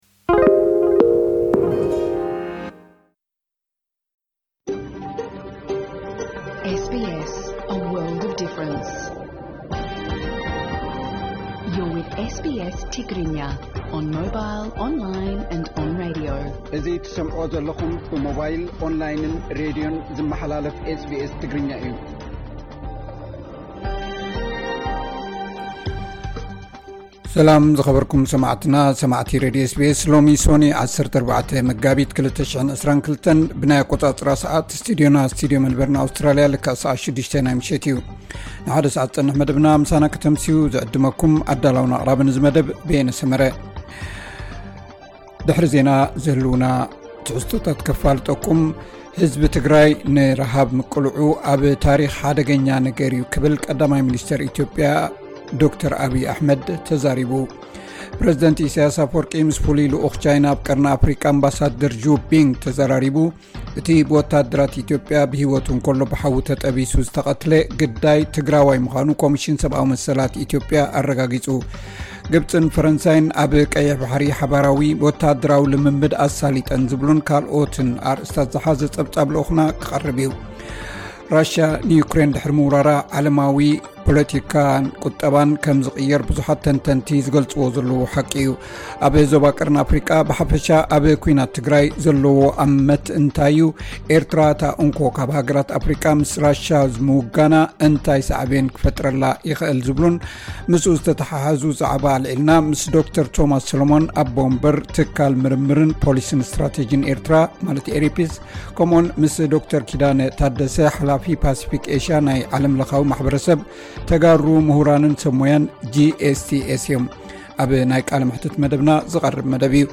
"ሩስያን ዩክረይንን ክዛተያ እየን" (ዕለታዊ ዜና SBS ትግርኛ 14 መጋቢት 2022)